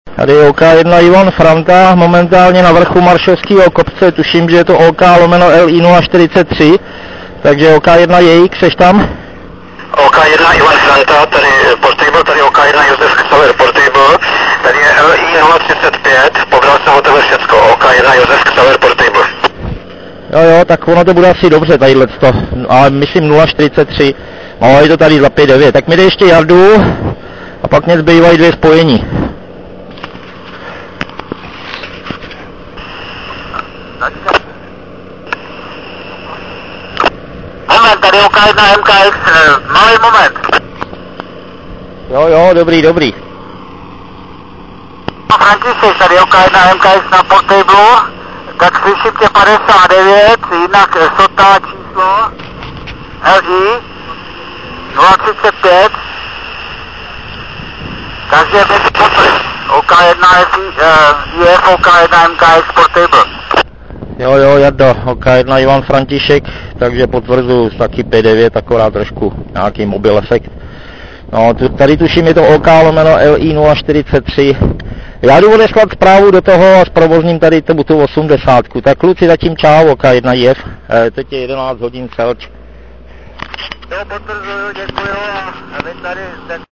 Nahrávky nejsou nejkvalitnější.
Nahrávku jsem musel dělat tak, že jsem "špuntové" sluchátko přikládal na mp3 nahravač.